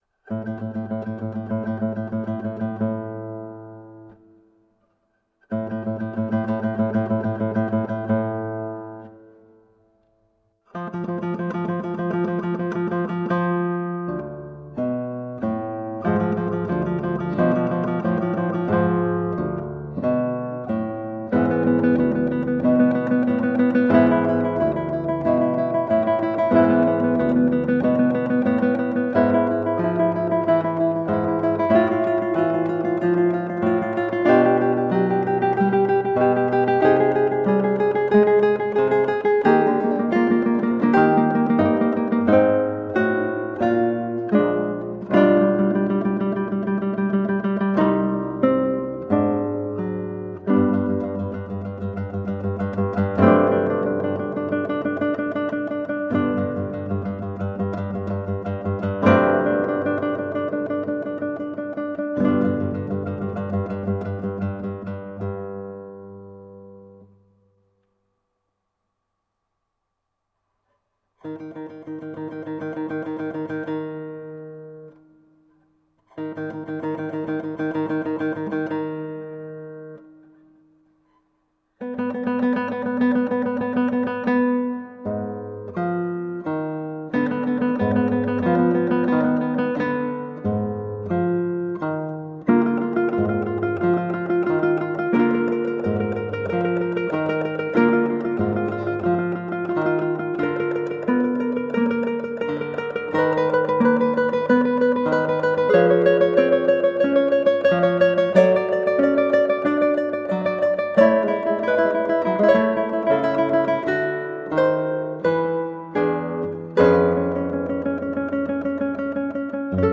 Colorful classical guitar.
Classical Guitar